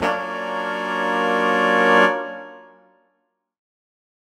Index of /musicradar/undercover-samples/Horn Swells/D
UC_HornSwell_Dmin6maj7.wav